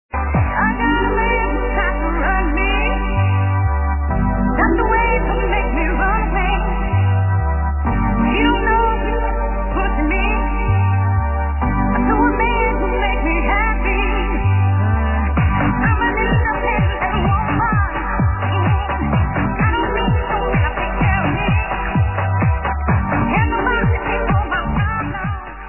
real old tune